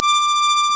Harmonica Sound Effect
Download a high-quality harmonica sound effect.
harmonica.mp3